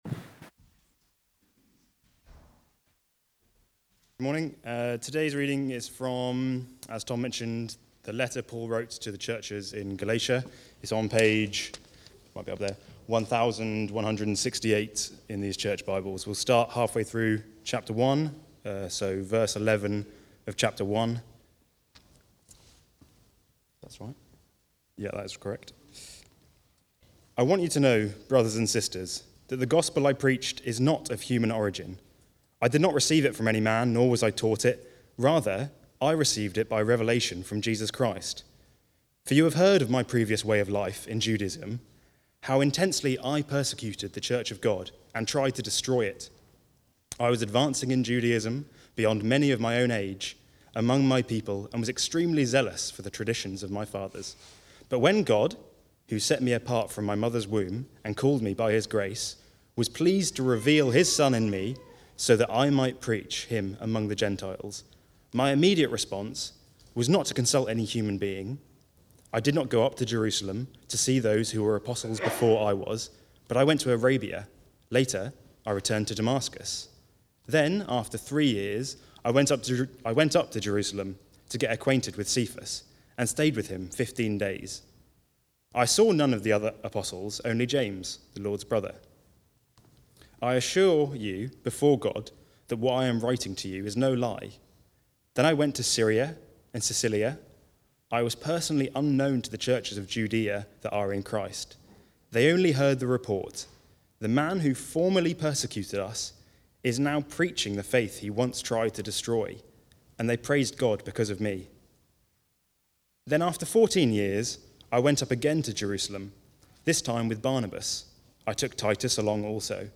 Preaching
The Gospel For All (Galatians 1:11-2:10) from the series Galatians - the Glorious Gospel. Recorded at Woodstock Road Baptist Church on 15 September 2024.